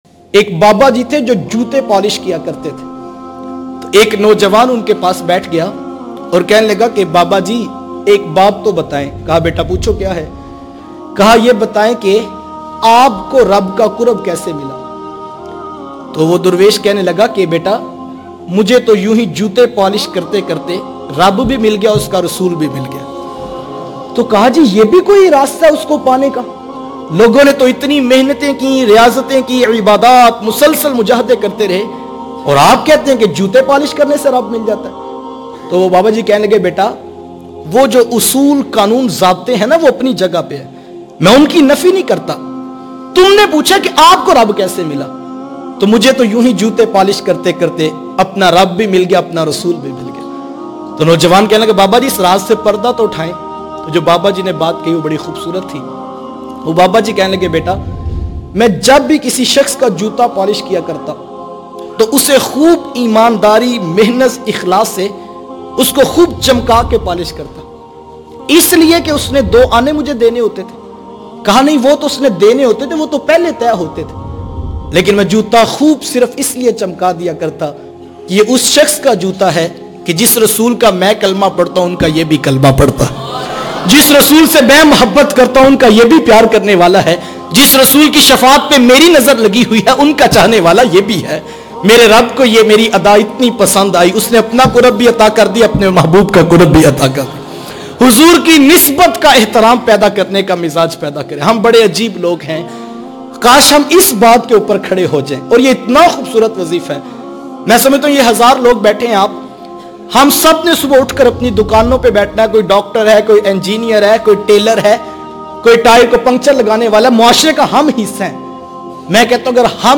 Mochi Allah ka wali kaisy bana bayan mp3